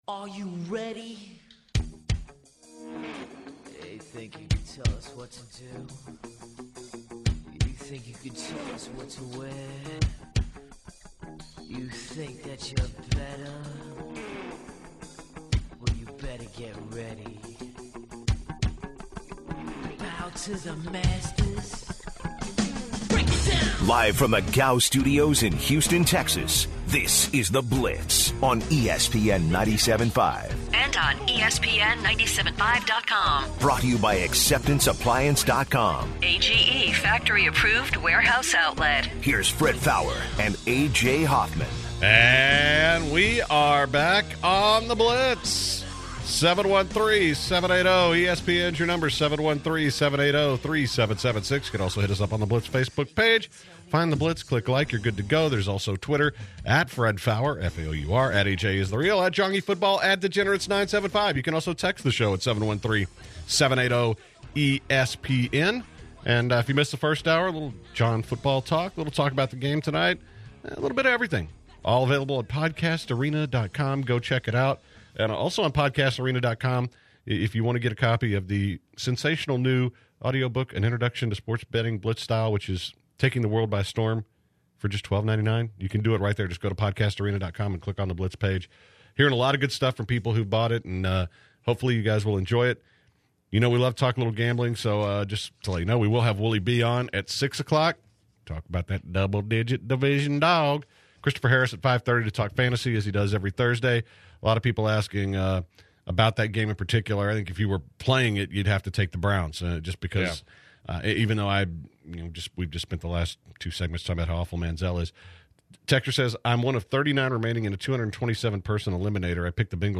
To open the second hour, the guys discuss which NFL coaches are likely to get fired.